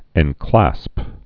(ĕn-klăsp)